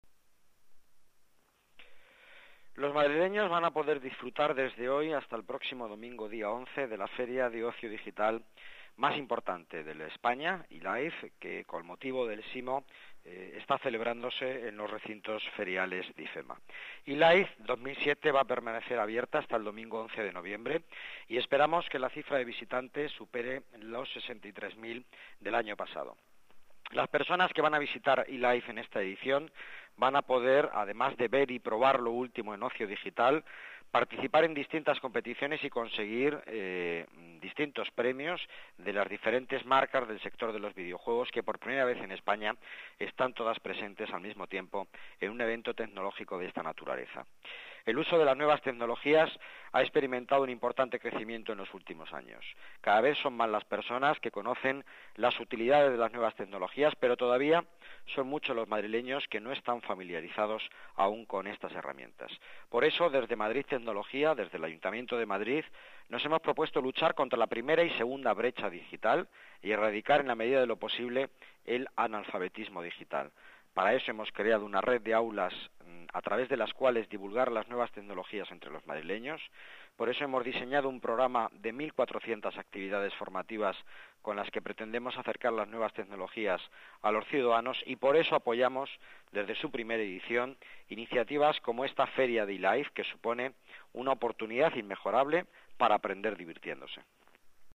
Nueva ventana:Declaraciones de Miguel Ángel Villanueva, delegado de Economía y Empleo